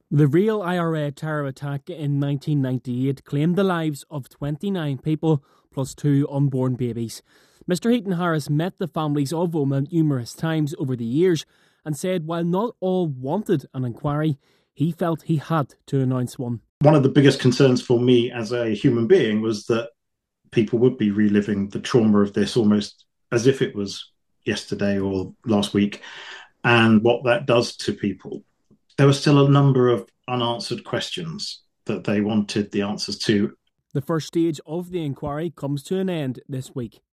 Former Secretary of State Chris Heaton-Harris spoke to reporter